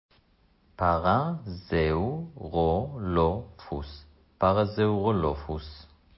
פר-זאו-רו-לו-פוס